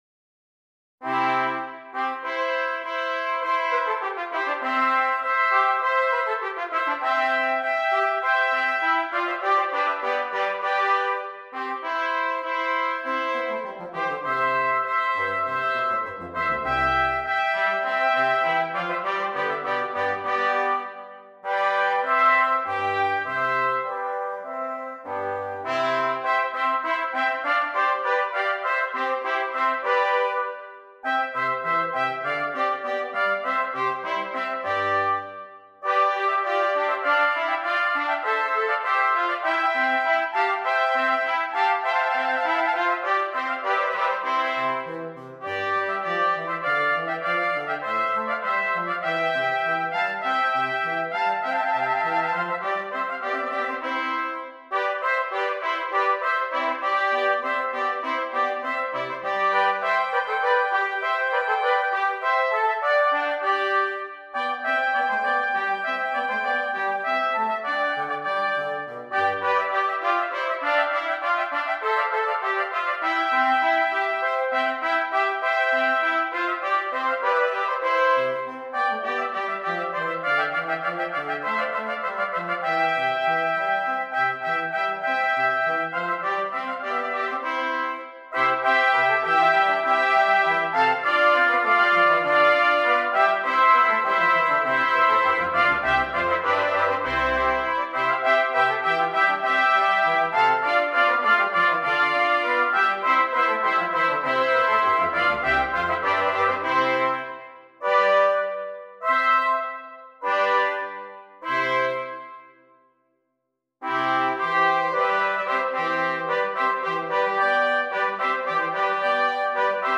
6 Trumpets and Bass Clef Instrument
trumpet ensemble setting